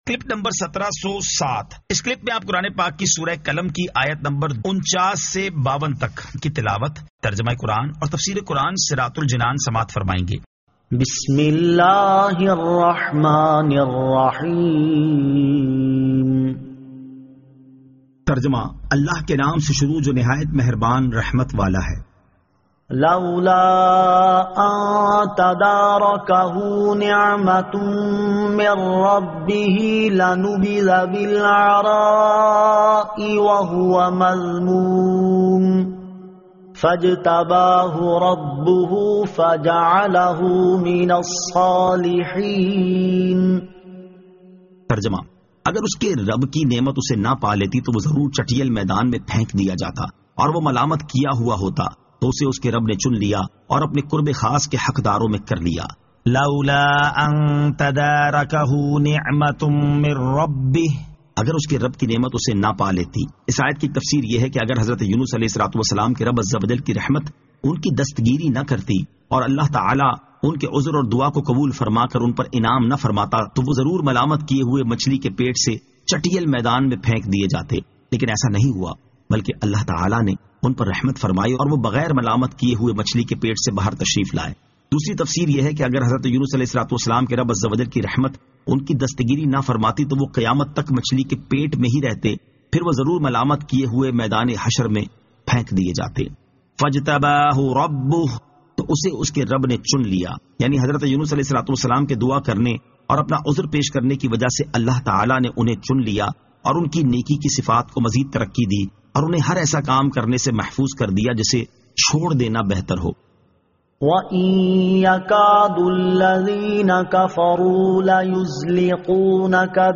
Surah Al-Qalam 49 To 52 Tilawat , Tarjama , Tafseer